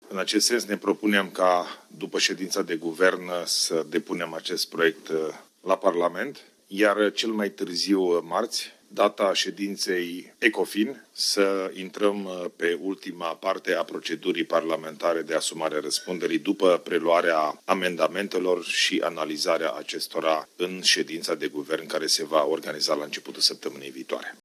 Proiectul de lege privind pachetul de măsuri fiscal-bugetare va fi trimis astăzi la Parlament a declarat premierul Ilie Bolojan